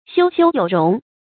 休休有容 注音： ㄒㄧㄨ ㄒㄧㄨ ㄧㄡˇ ㄖㄨㄙˊ 讀音讀法： 意思解釋： 形容君子寬容而有氣量。